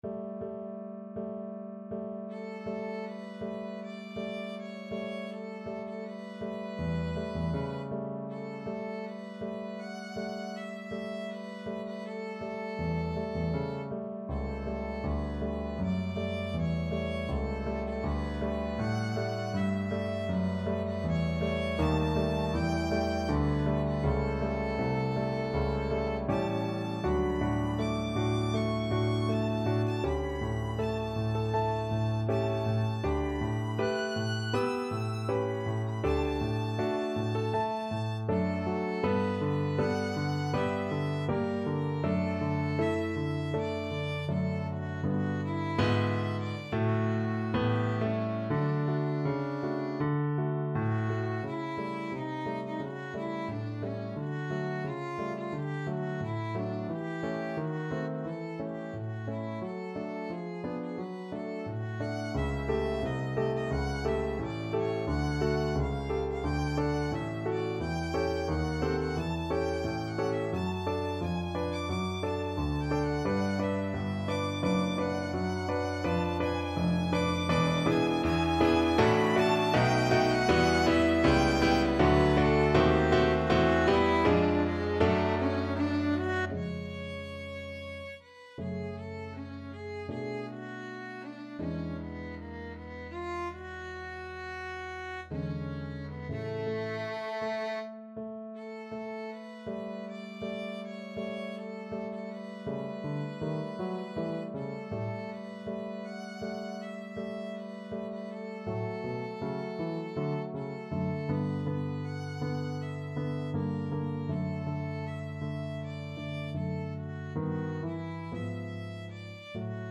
Violin
D major (Sounding Pitch) (View more D major Music for Violin )
4/4 (View more 4/4 Music)
Andante espressivo
elgar_mot_damour_VLN.mp3